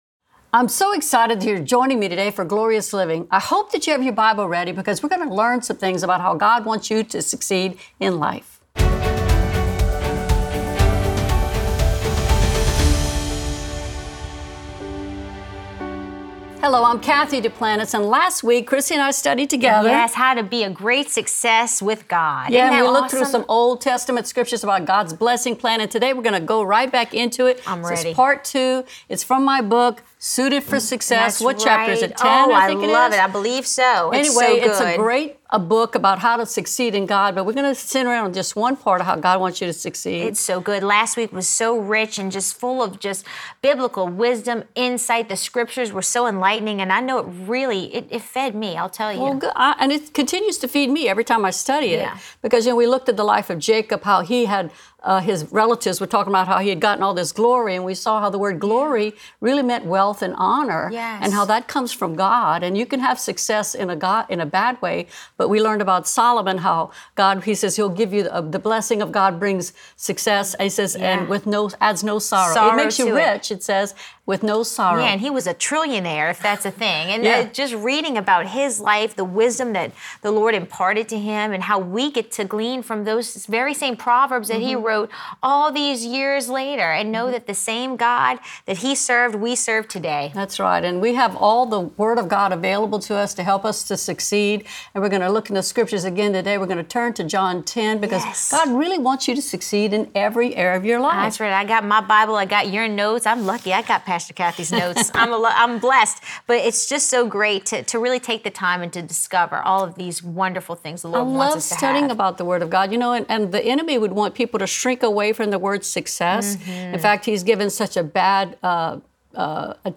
in Studio C for Part 2 of this dynamic teaching. Remember, you will be successful when you put your trust in God!